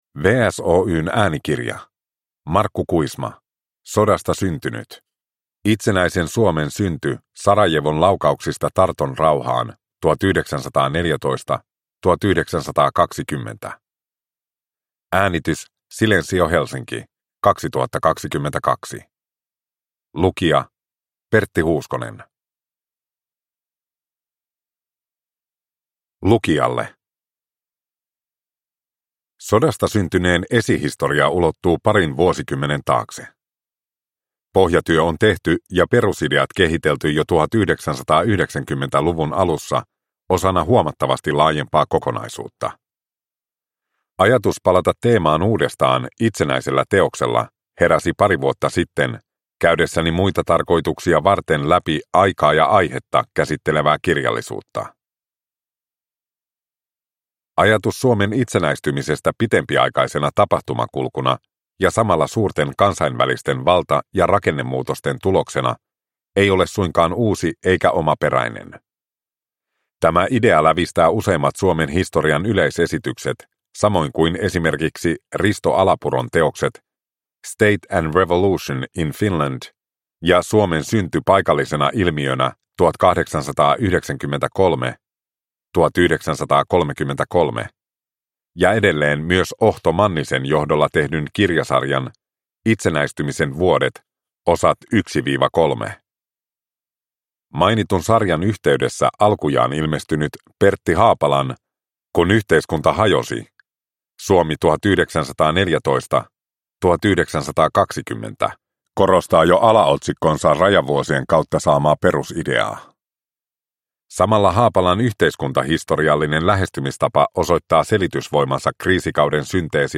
Sodasta syntynyt – Ljudbok – Laddas ner